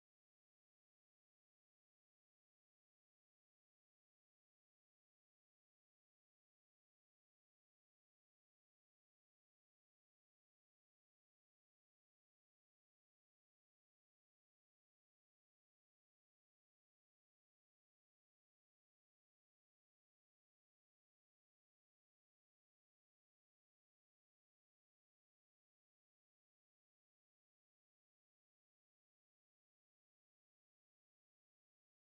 Kindertänze: Mariechen auf dem Stein
Tonart: G-Dur
Taktart: 2/4
Tonumfang: große Sexte